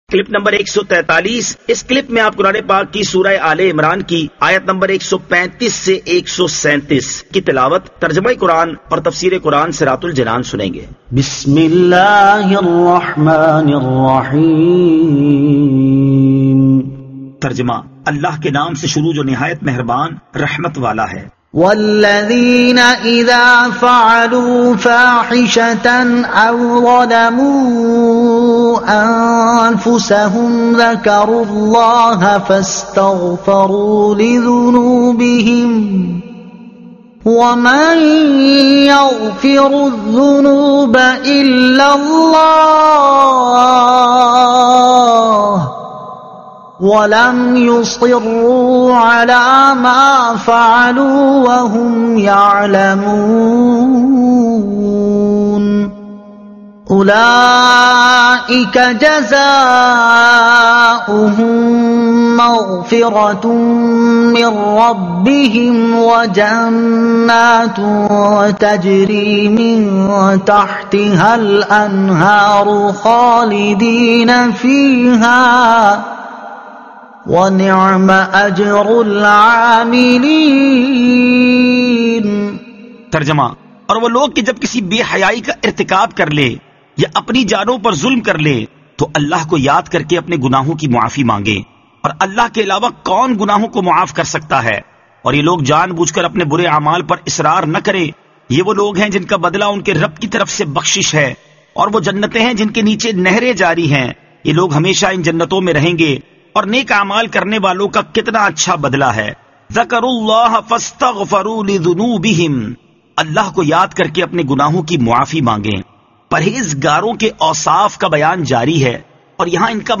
Surah Aal-e-Imran Ayat 135 To 137 Tilawat , Tarjuma , Tafseer